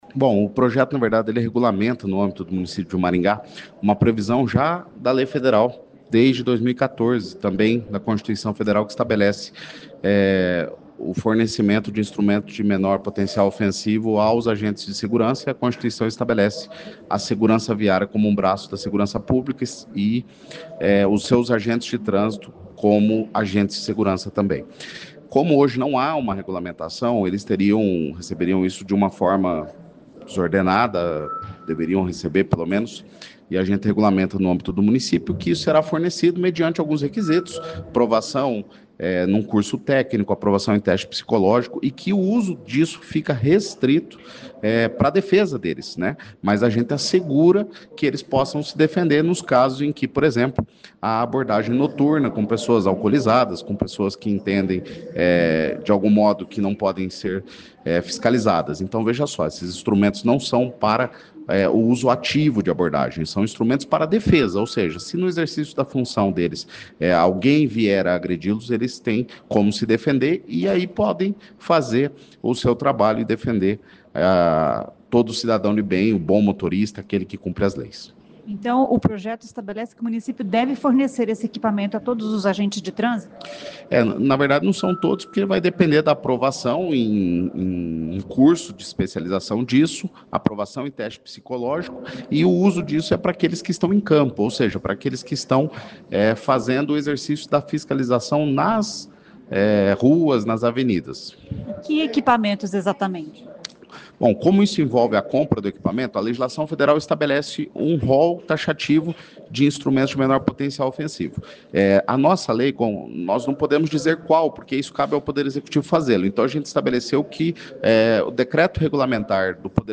O autor da lei é o vereador Jean Marques (Podemos).